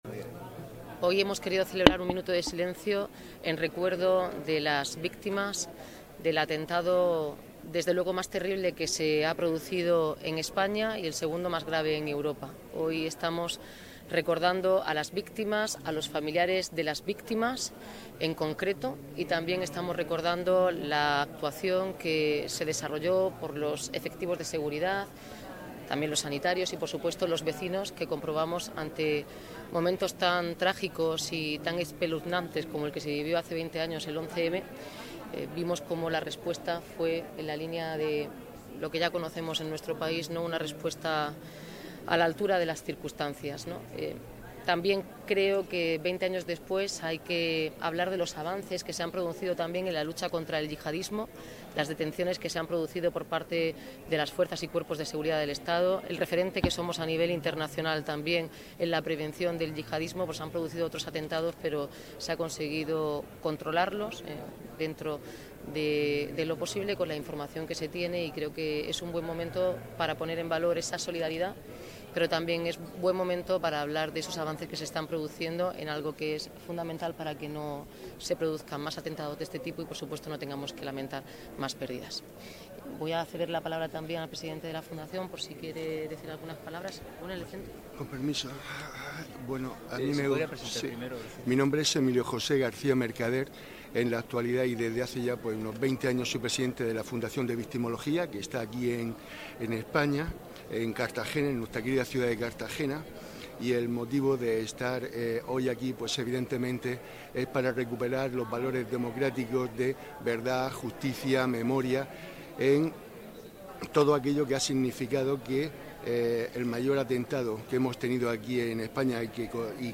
Declaraciones
El Ayuntamiento de Cartagena ha guardado un minuto de silencio este lunes, 11 de marzo, Día Europero en Memoria de las Víctimas del Terrorismo, que ha servido también de recuerdo a las víctimas del atentado terrorista de Madrid ocurrido hace 20 años. La alcaldesa Noelia Arroyo ha presidido este recuerdo que ha tenido lugar a las puertas del Palacio Consistorial congregando a integrantes de la Corporación municipal, autoridades locales y personal municipal.